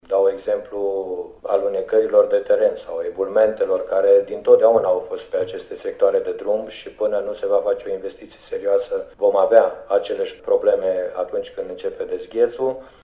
Autorităţile judeţene sunt depăşite de situaţie, cel puţin în această speţă, afirmă vicepreşedintele Consiliului Judeţean Caraş-Severin, Ilie Iova: